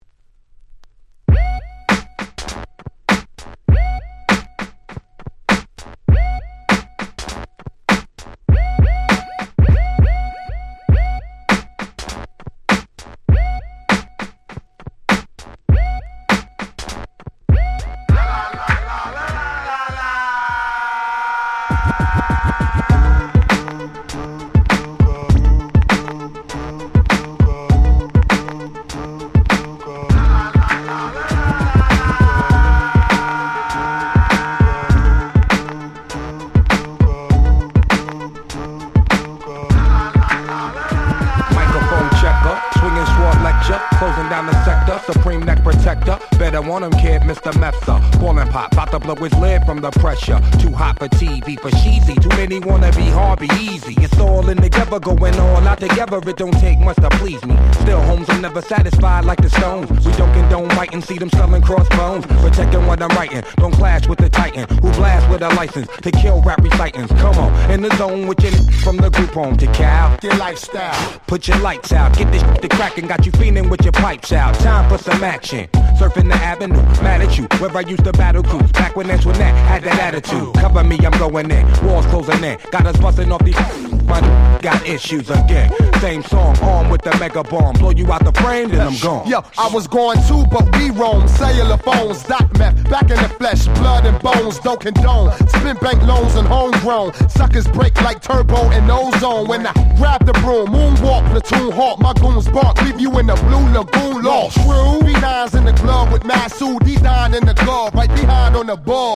自分が当時好んで使用していた曲を試聴ファイルとして録音しておきました。